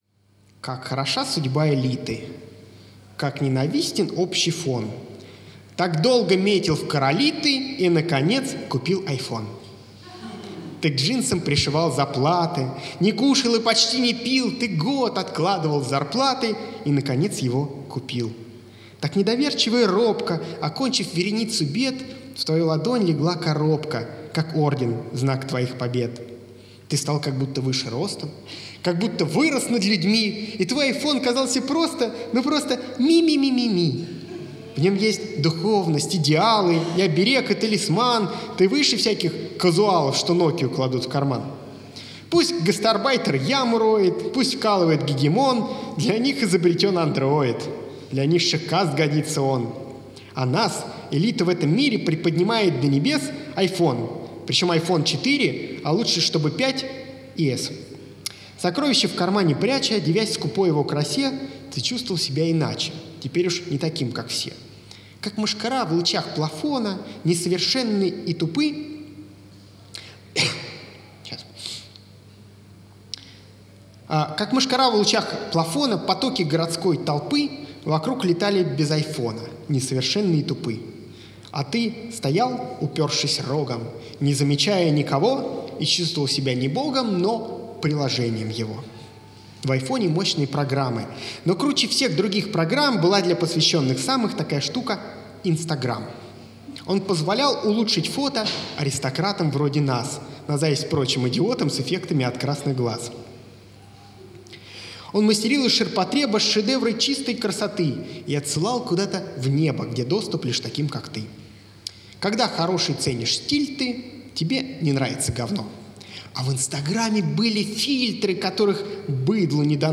Здесь собрано все, что было записано и имеет минимально приемлемое техническое качество.